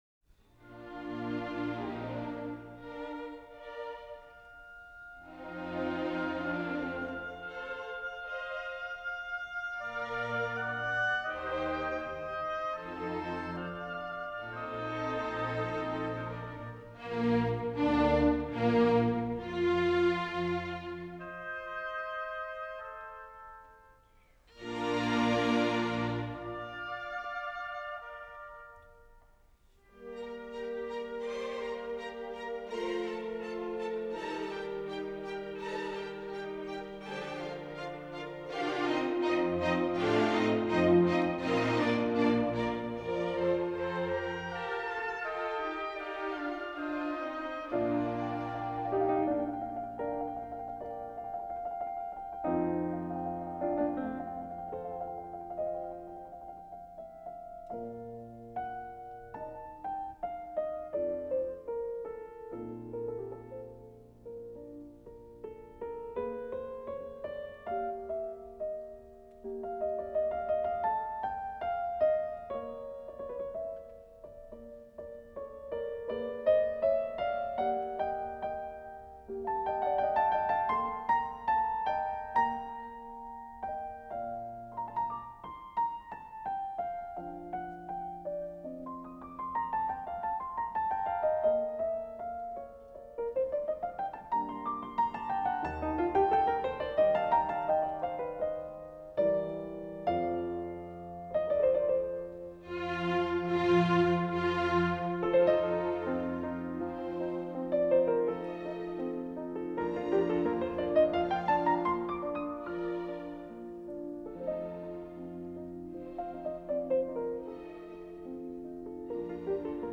Index of /storage/0C1C9E5E1C9E431E/MUSIC 2nd/New created/Classics/[ M4A ]/Mozart Concertos for Two and Three Pianos